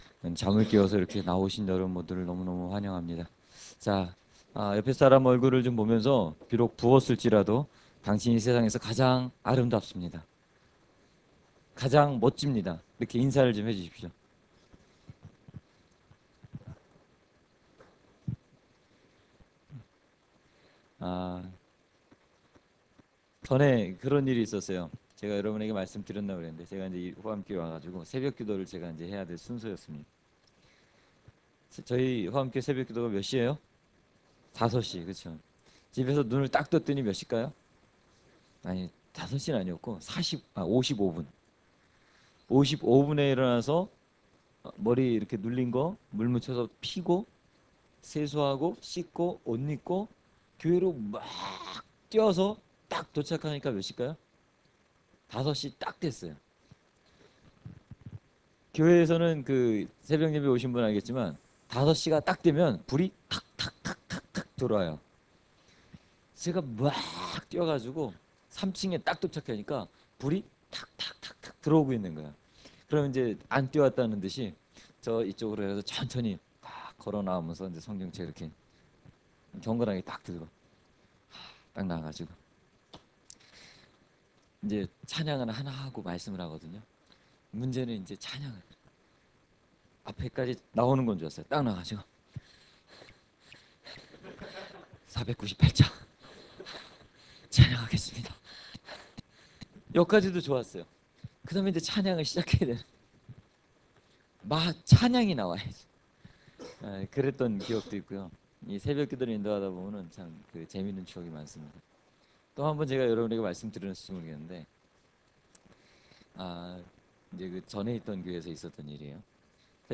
설교 말씀
청년부 동계 수련회 2월 11일 새벽 집회